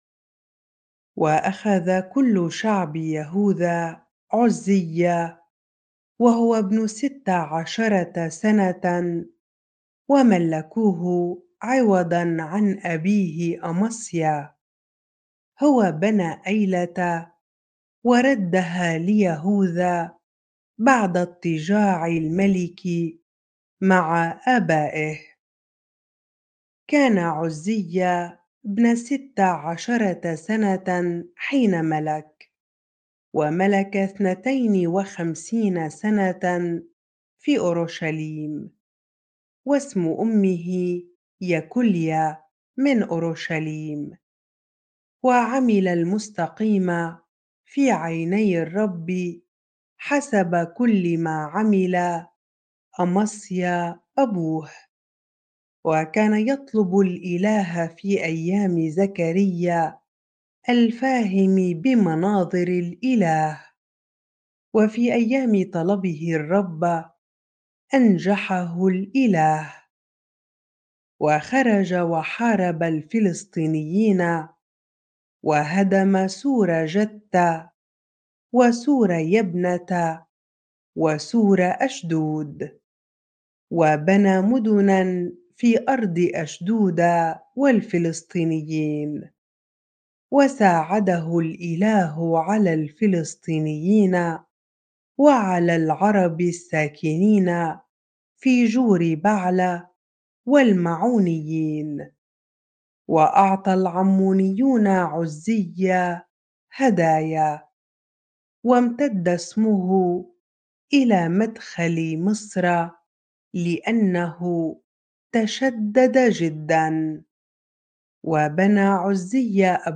bible-reading-2 Chronicles 26 ar